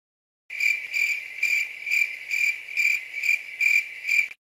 Awkward Cricket